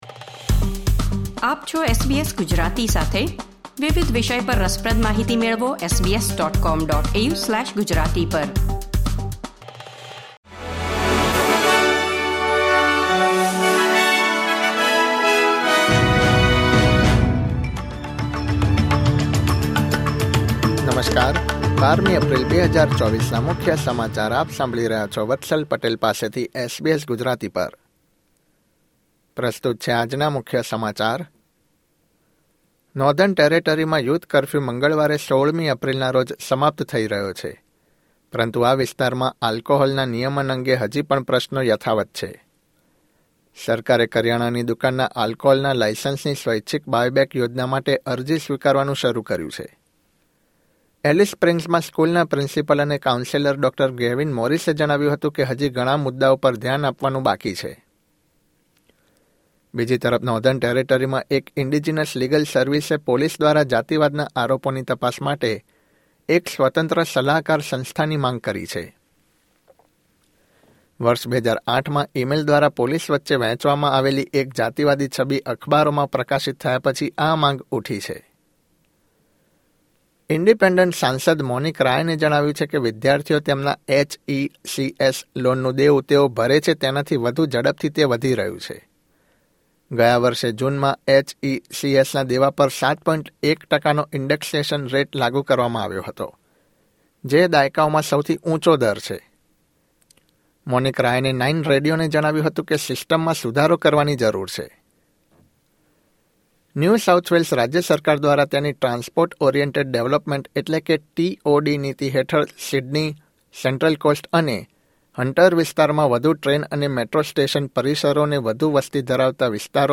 SBS Gujarati News Bulletin 12 April 2024